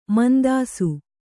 ♪ mandāsu